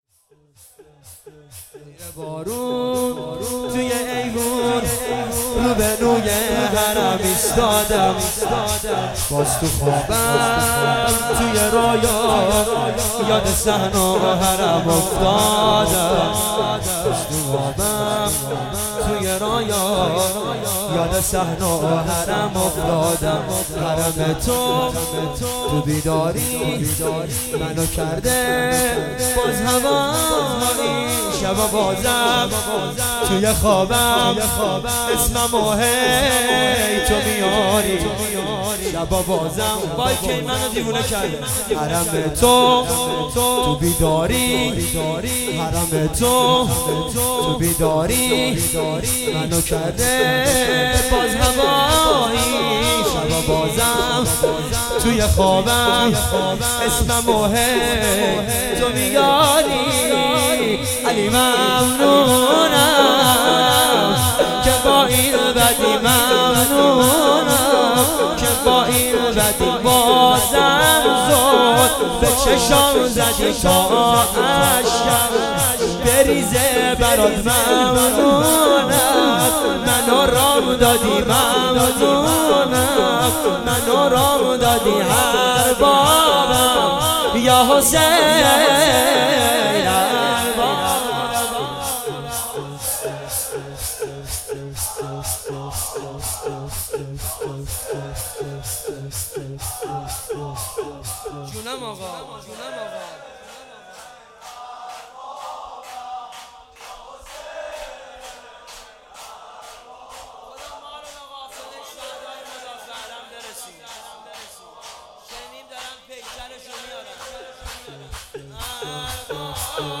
خیمه گاه - هیئت جوانان حضرت ابوالفضل العباس - شور
هیئت جوانان حضرت ابوالفضل العباس